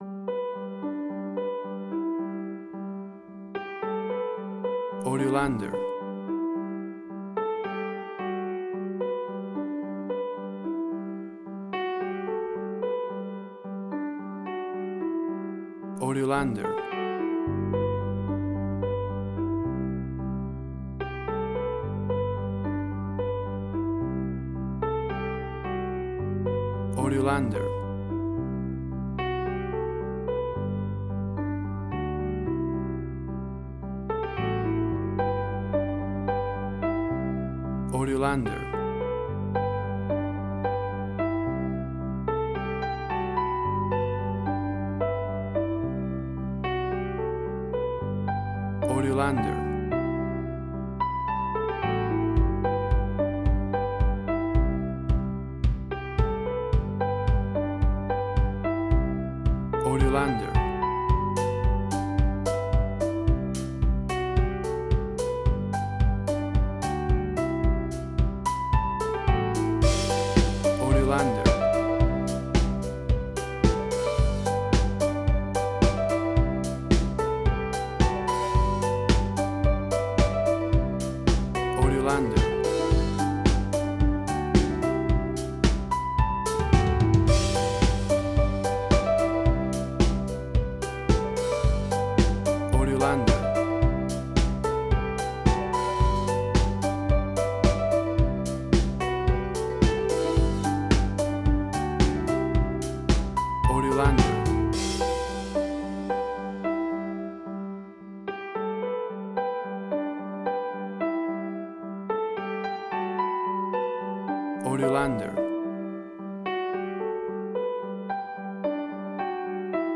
WAV Sample Rate: 16-Bit stereo, 44.1 kHz
Tempo (BPM): 110